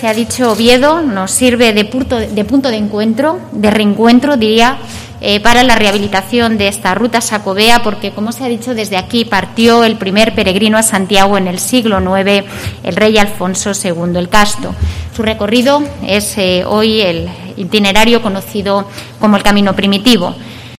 La Ministra Reyes Maroto recuerda al primer peregrino Alfonso II